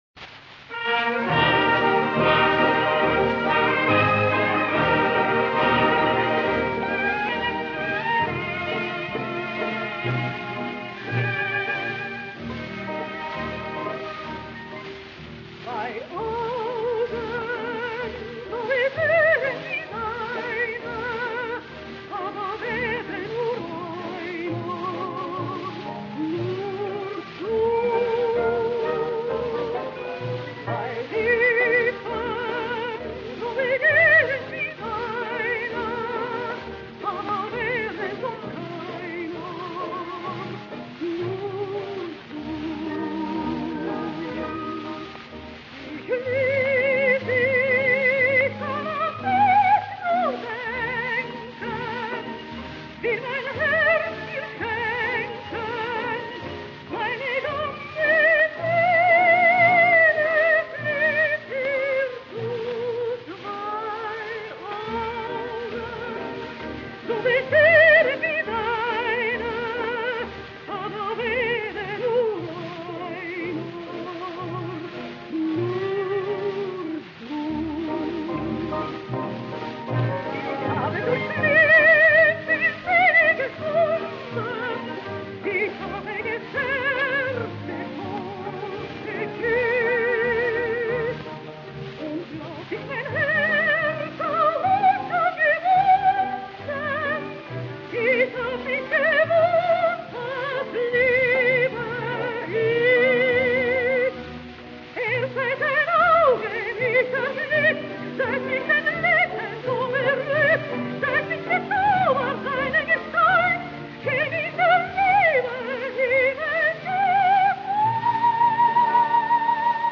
Slow-Fox